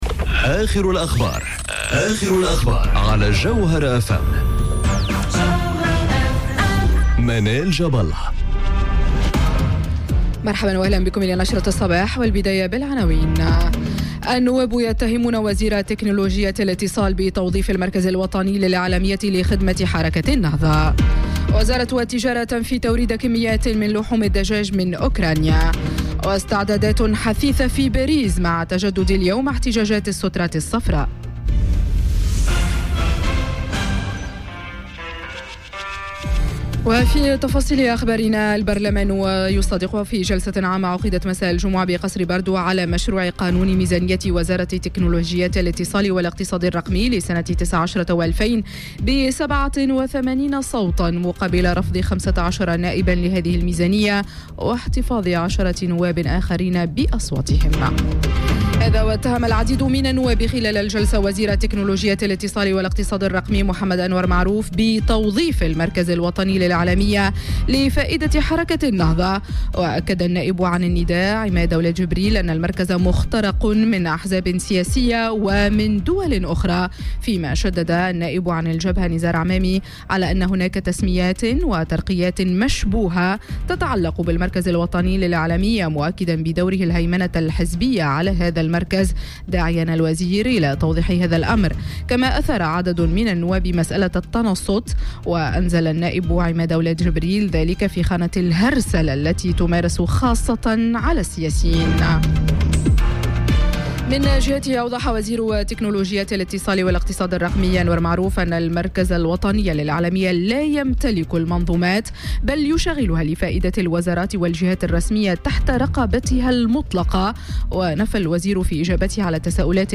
نشرة أخبار السابعة صباحا ليوم السبت 1 ديسمبر 2018